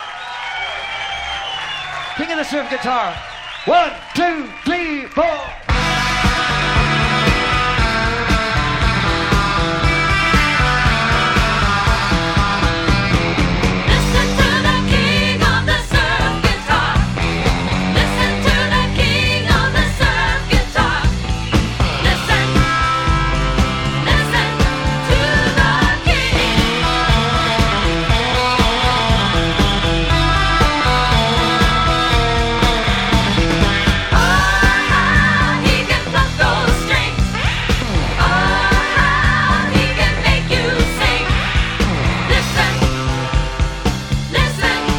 終始ゴキゲンなロックンロールを展開した痛快なライブ盤。
Rock, Surf　USA　12inchレコード　33rpm　Stereo